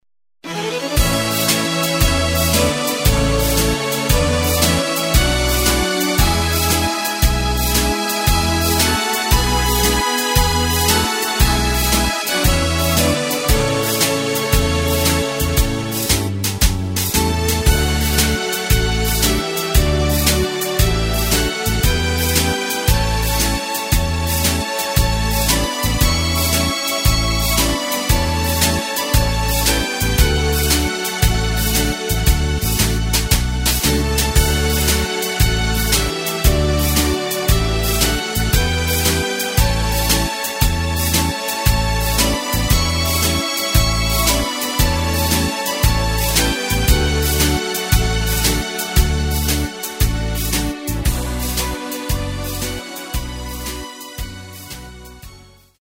Takt:          4/4
Tempo:         115.00
Tonart:            A
Schlager aus dem Jahr 1972!
Playback mp3 mit Lyrics